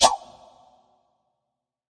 铁匠-飞镖攻击音效.mp3